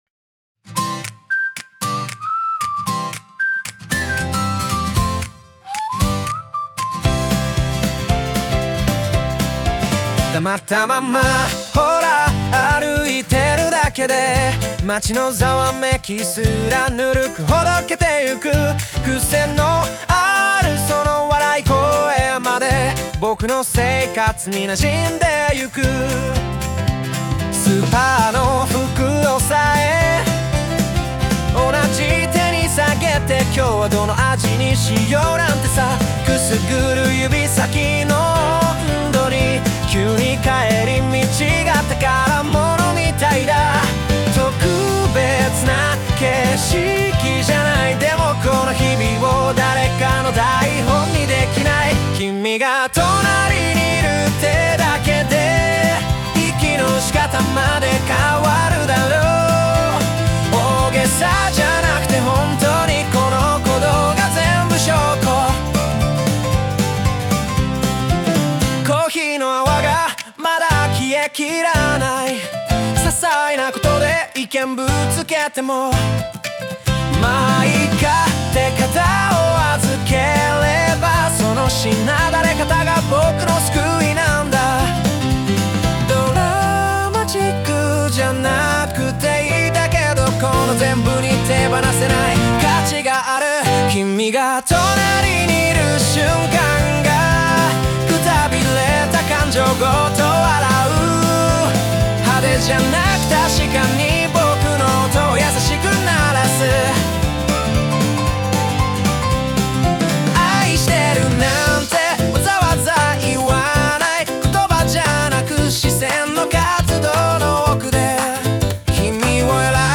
邦楽男性ボーカル著作権フリーBGM ボーカル
著作権フリーオリジナルBGMです。
男性ボーカル（邦楽・日本語）曲です。